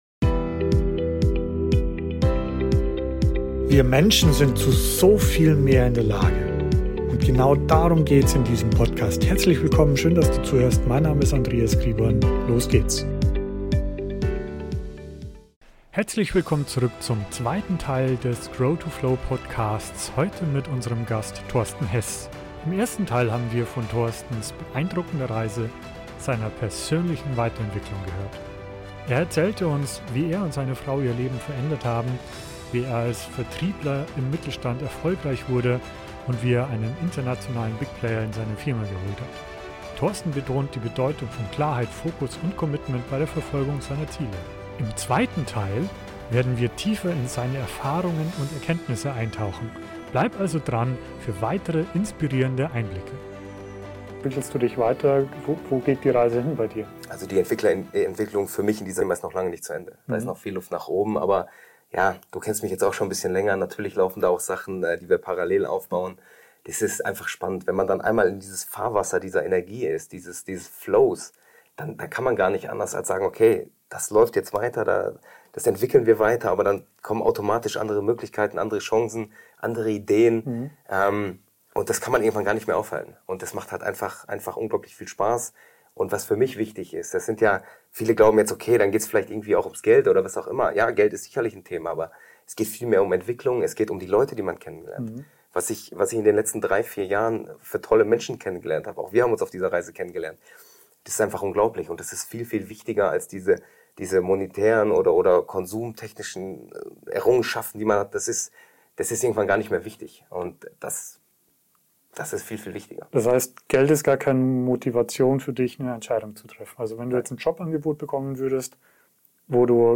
#13 Neue Möglichkeiten | Interview